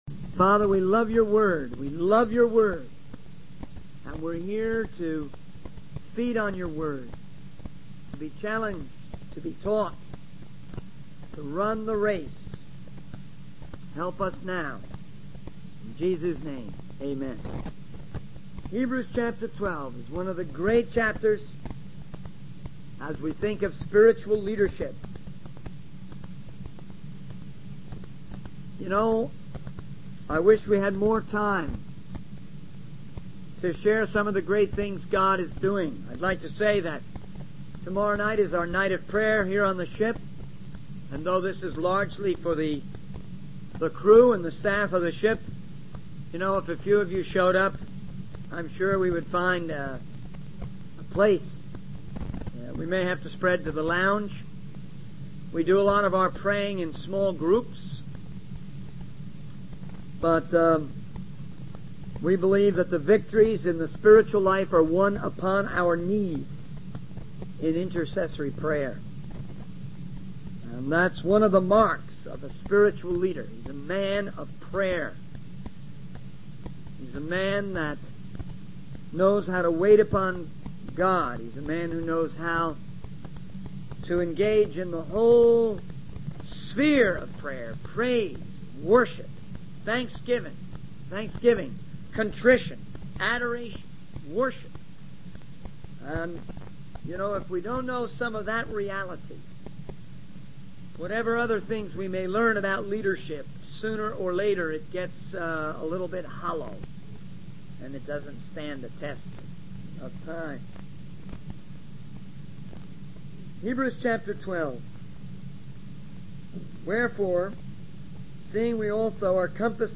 In this sermon, the speaker emphasizes the importance of maintaining balance in various aspects of life. He discusses the need for balance between work and recreation, fellowship and worship, and church life and family. The speaker also highlights the significance of using our minds and talents to create and serve God.